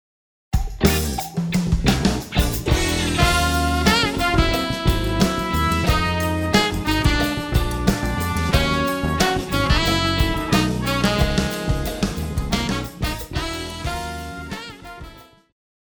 爵士
旋律和絃譜,電吉他,中音薩克斯風
樂團
演奏曲
爵士搖滾,時尚爵士,現代爵士,融合爵士
獨奏與伴奏,五重奏
有主奏
有節拍器